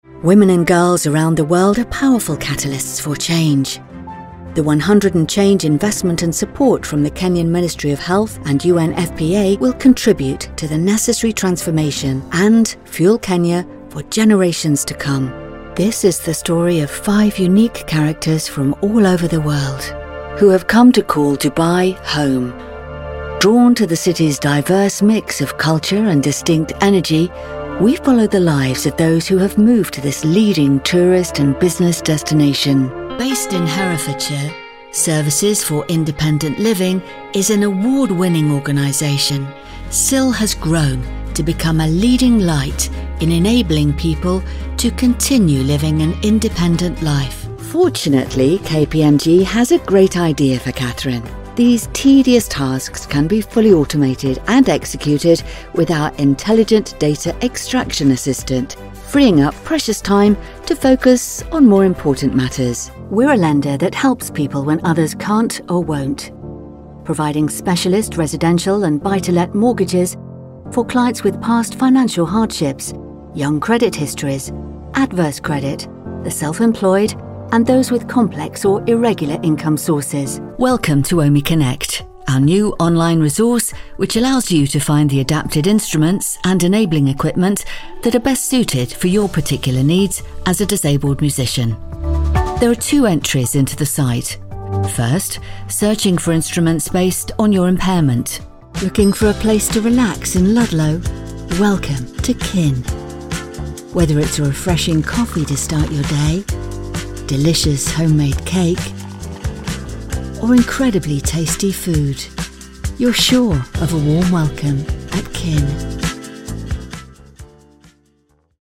What you hear is what I am: natural, friendly and professional, with a ready smile.